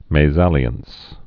(mā-zălē-əns, māză-lyäɴs)